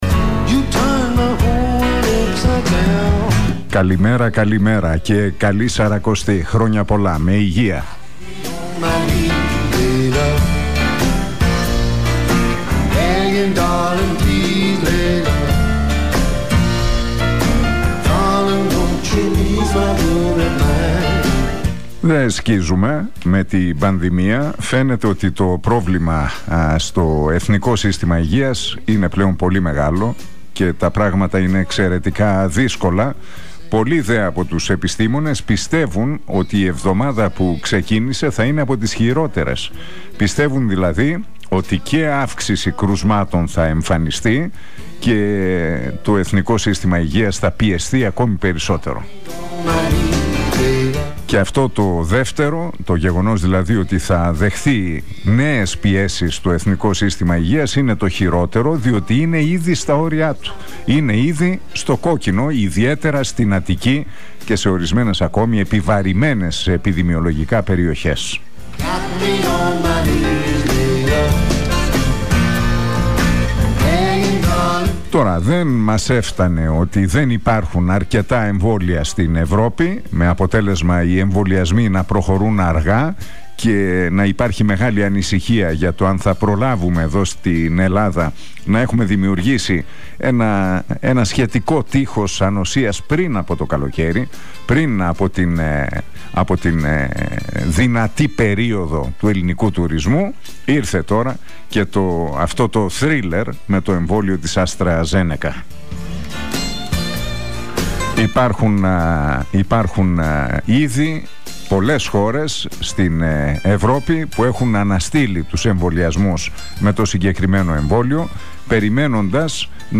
Ακούστε το σημερινό σχόλιο του Νίκου Χατζηνικολάου στον Realfm 97,8...